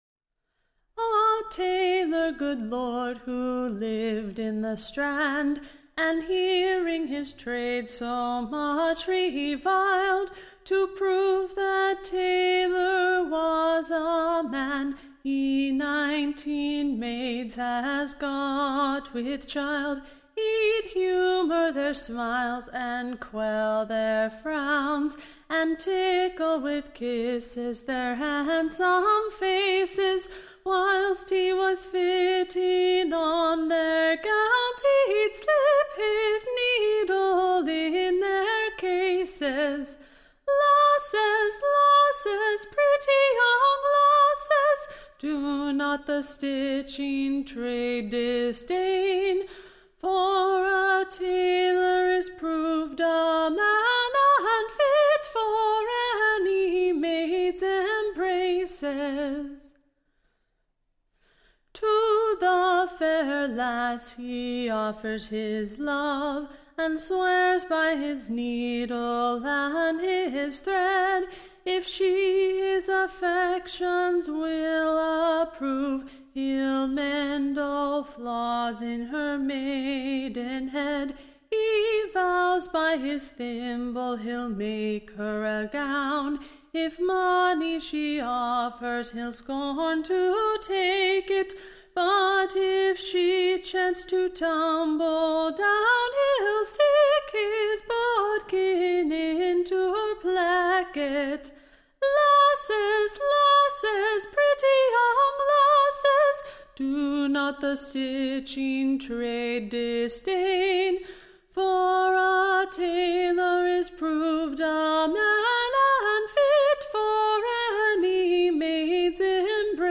Recording Information Ballad Title The Rampant Taylor, / OR THE / Nineteen Lasses in Distress Tune Imprint To the Tune of, March, Boys, &c. Standard Tune Title March, Boys Media Listen 00 : 00 | 4 : 48 Download P5.125v.mp3 (Right click, Save As)